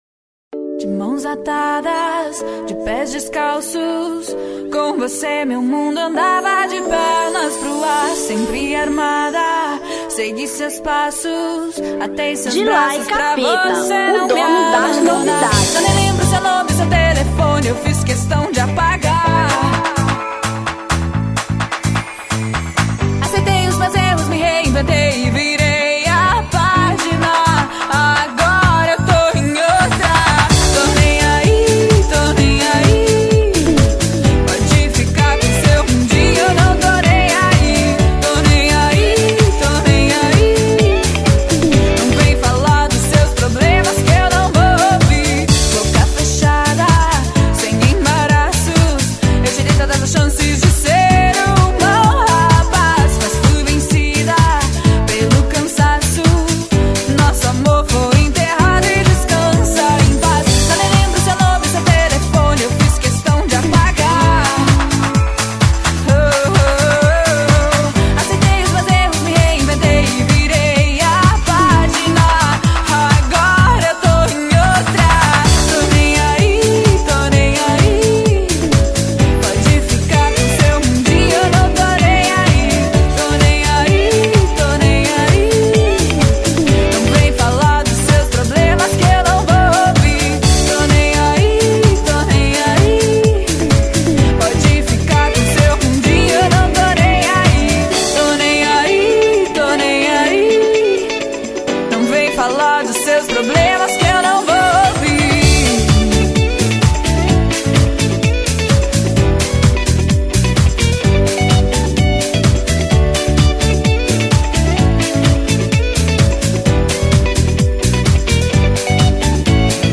Samba 2003